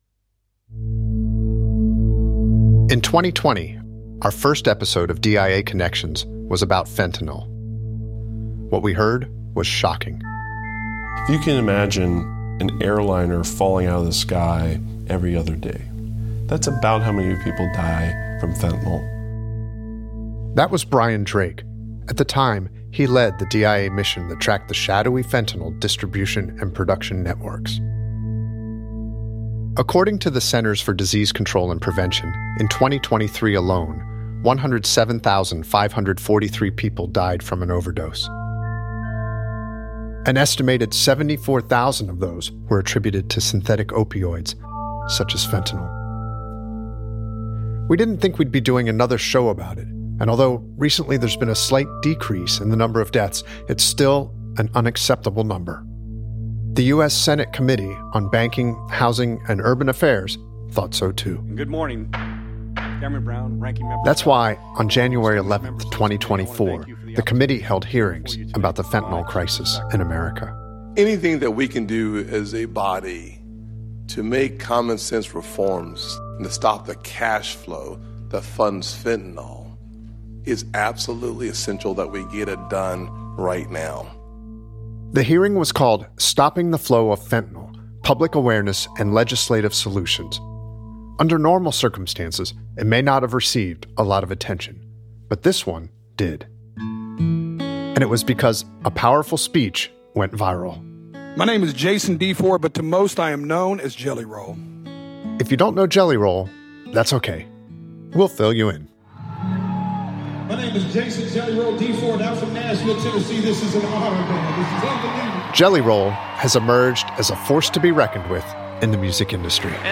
Fentanyl is the leading cause of death for Americans between the ages of 18 and 45. On this episode, you’ll hear a DIA counter narcotics officer discuss the agency’s efforts to combat the most devastating drug epidemic in U.S. history. We also spoke with Mariana Van Zeller, an investigative reporter who went deep inside the fentanyl pipeline to Mexico’s cartel country to see how it’s produced and trafficked into the United States.